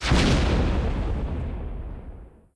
explode-medium.ogg